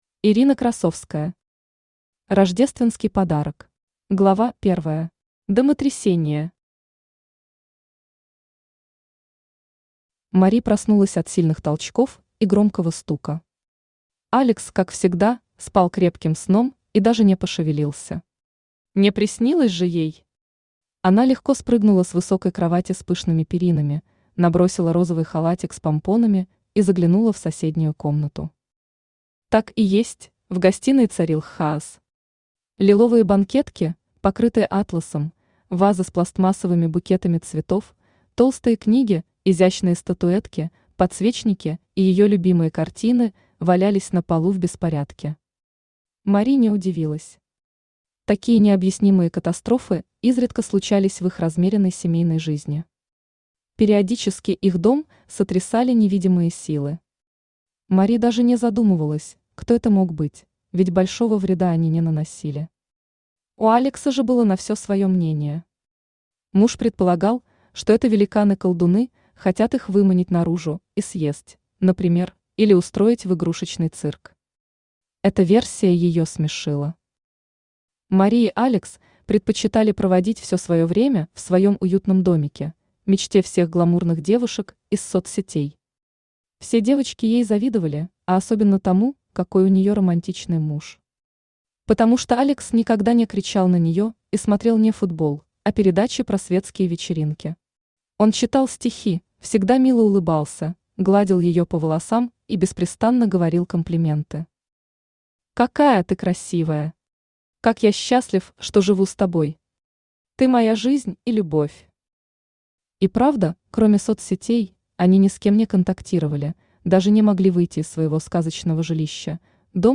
Аудиокнига Рождественский подарок | Библиотека аудиокниг
Aудиокнига Рождественский подарок Автор Ирина Красовская Читает аудиокнигу Авточтец ЛитРес.